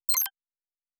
Sci-Fi Sounds / Interface / Digital Click 15.wav
Digital Click 15.wav